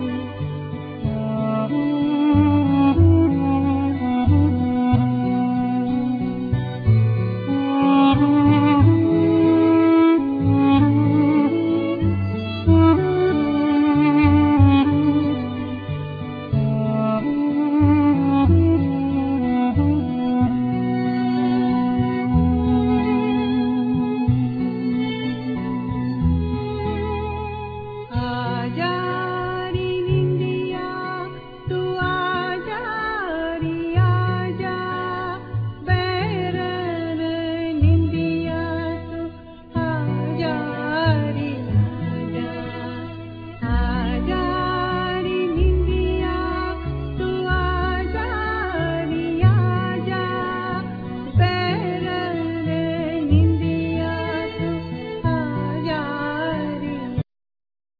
Vocals
Tabla
Piano,Keyboards
Sitar
Violin,Viola,Cello,Contra bass